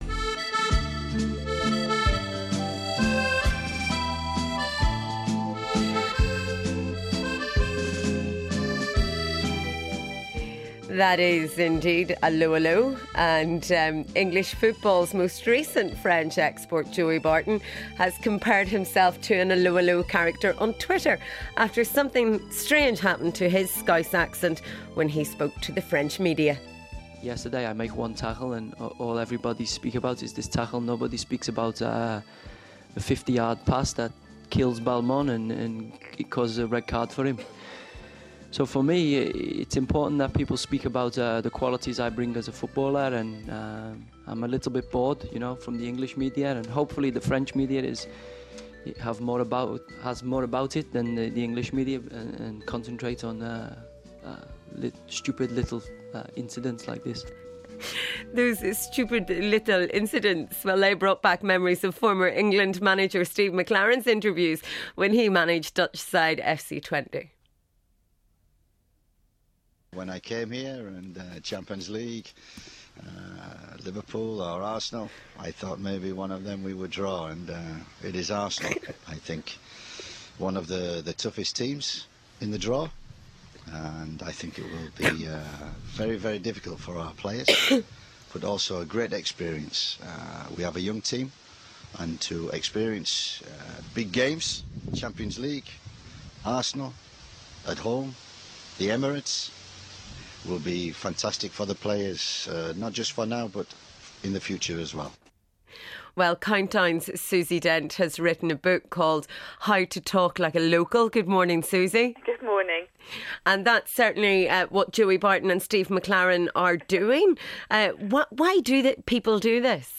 Allo' Allo' - What do you think of Joey Barton's French accent?